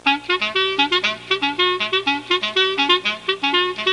Squeaky Wheel Intro Sound Effect
Download a high-quality squeaky wheel intro sound effect.
squeaky-wheel-intro.mp3